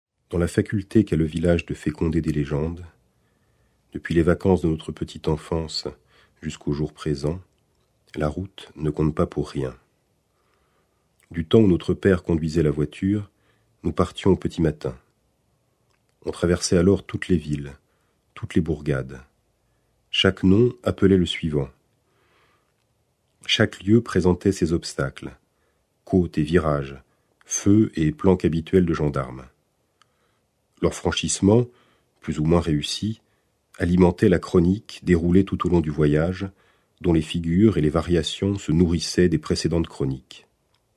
Click for an excerpt - Pays perdu de Pierre Jourde